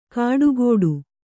♪ kāḍu gōḍu